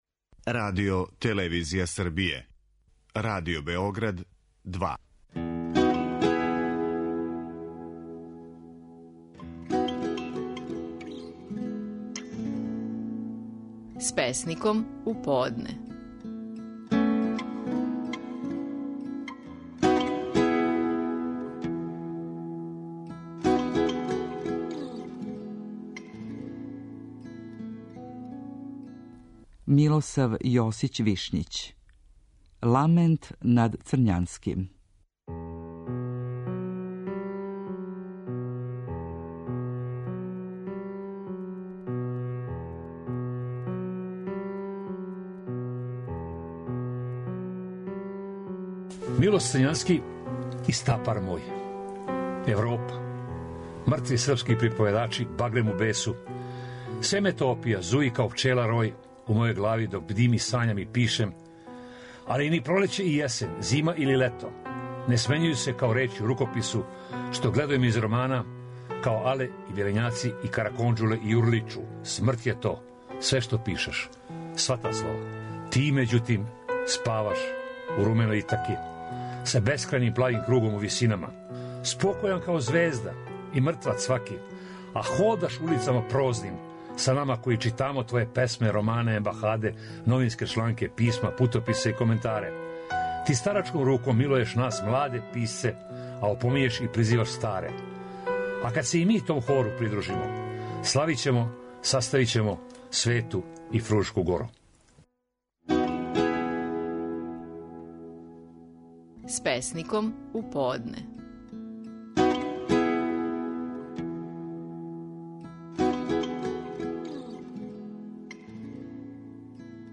Стихови наших најпознатијих песника, у интерпретацији аутора.
Милосав Јосић Вишњић говори песму „Ламент над Црњанским".